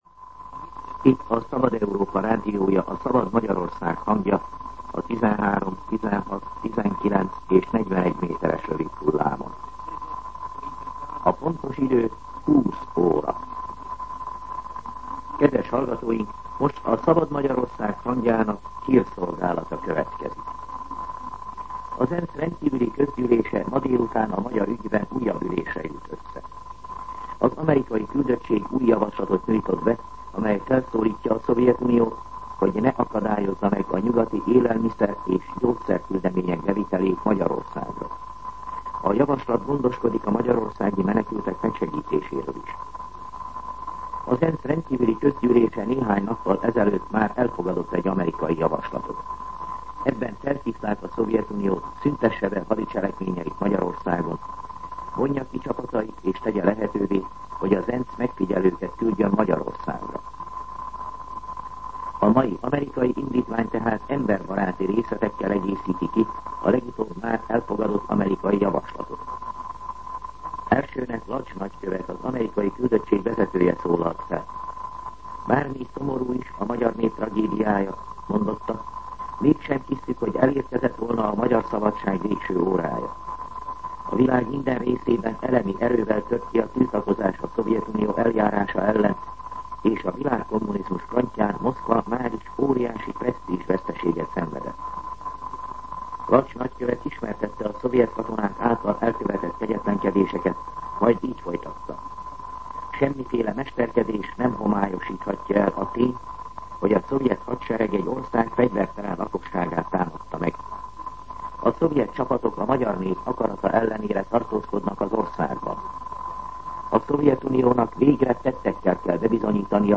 20:00 óra. Hírszolgálat